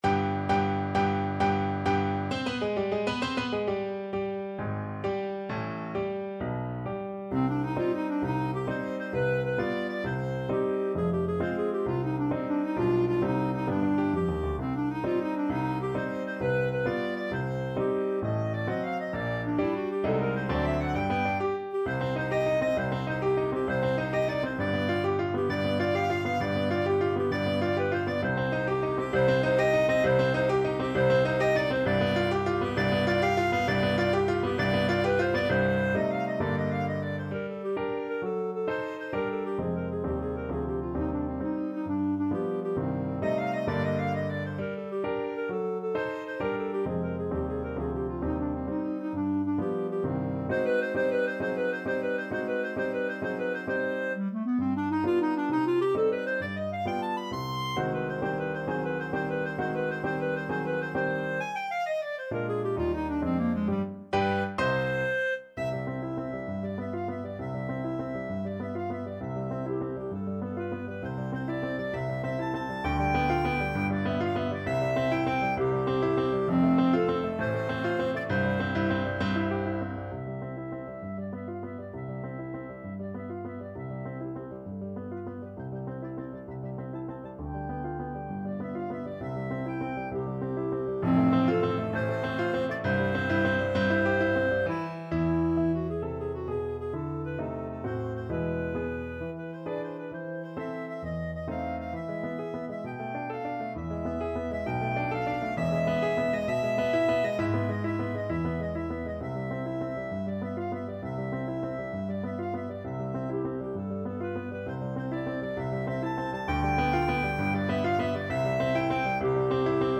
= 132 Allegro con spirito (View more music marked Allegro)
6/8 (View more 6/8 Music)
Classical (View more Classical Clarinet Music)